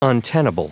Prononciation du mot untenable en anglais (fichier audio)